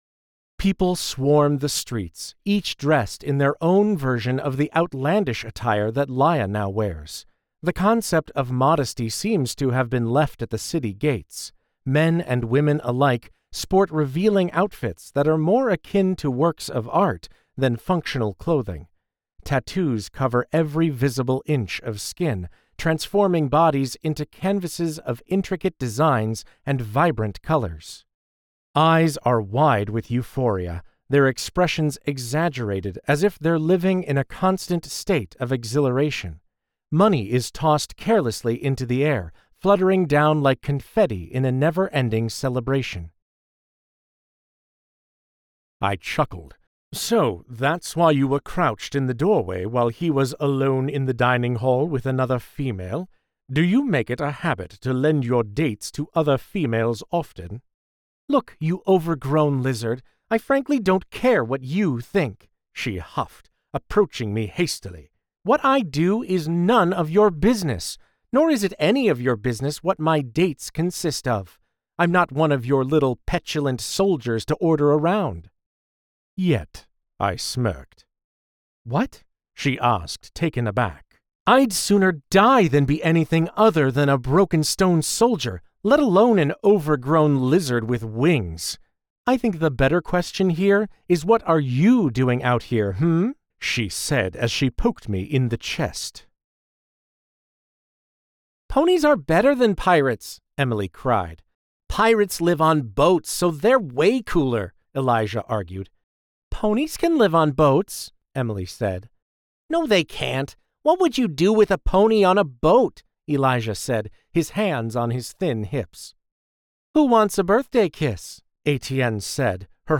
This Demo contains a wide variety of past recordings, including: Neutral Narration, Descriptive, Dialogue, Male and Female voices, Multiple Accents (English:RP, Irish:Cork, English:Ukranian), and Singing.